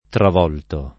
vai all'elenco alfabetico delle voci ingrandisci il carattere 100% rimpicciolisci il carattere stampa invia tramite posta elettronica codividi su Facebook travolgere [ trav 0 l J ere ] v.; travolgo [ trav 0 l g o ], -gi — coniug. come volgere ; part. pass. travolto [ trav 0 lto ]